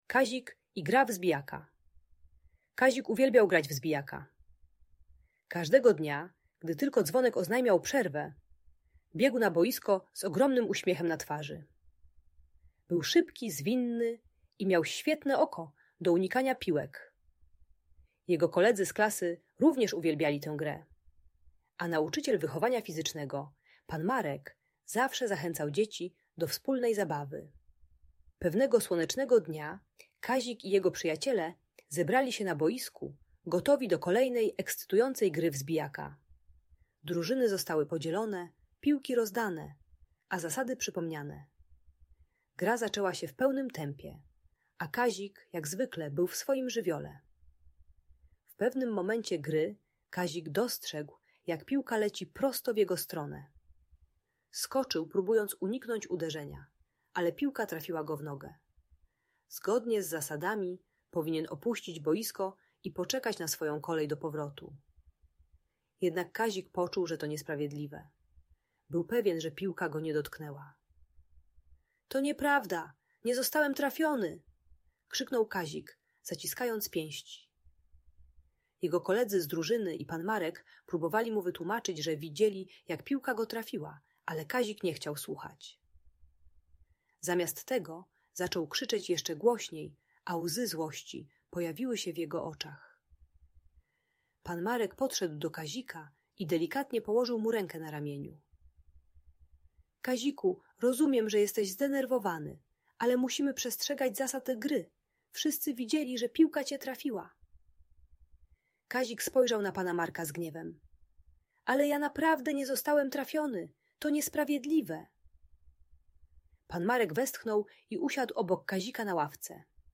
Kazik i Gra w Zbijaka - Audiobajka